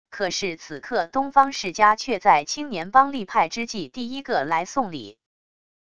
可是此刻东方世家却在青年帮立派之际第一个来送礼wav音频生成系统WAV Audio Player